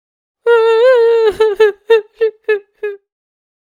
grandma cry sound.
cry.wav